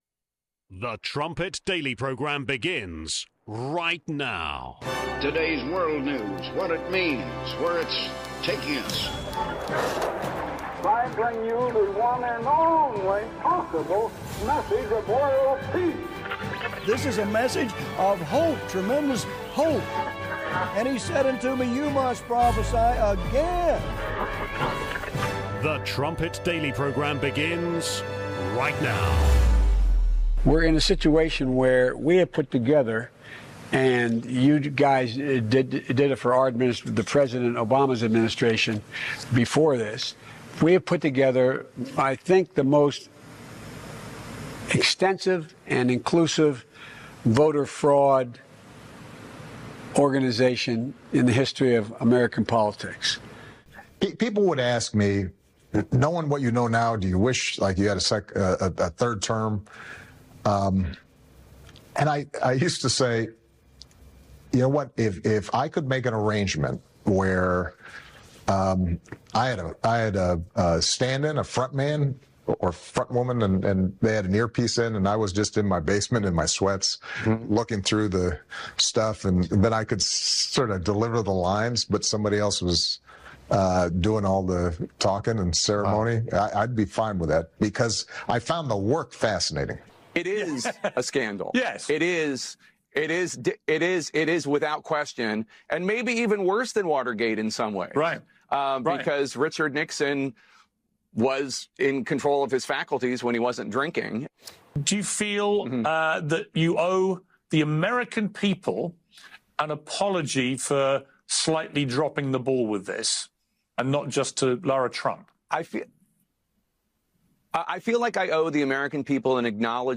23:30 Melanie Phillips Interview, Part 2 (29 minutes)